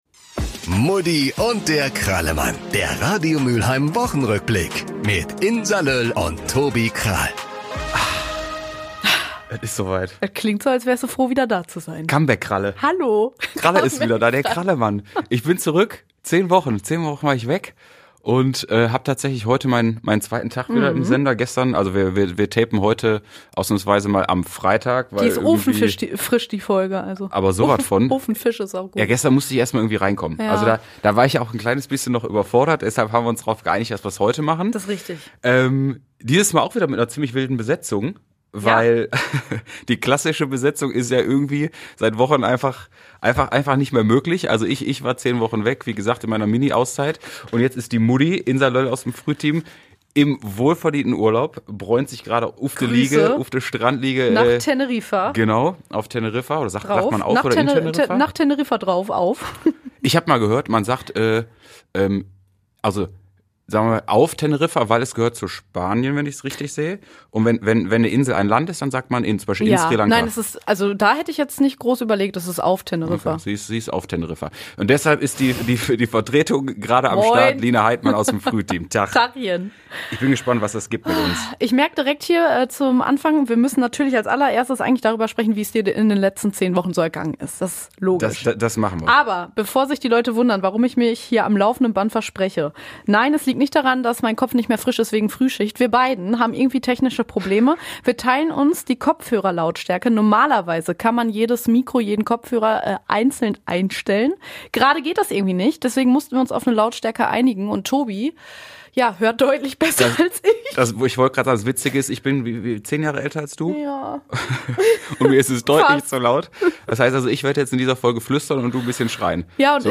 Deshalb gibt’s natürlich auch eine Live-Schalte mit unserer Außenkorrespondentin im Insel-Studio und die beiden klären die wichtigste Frage des Herbsts: Welches Piece tragen wir am liebsten?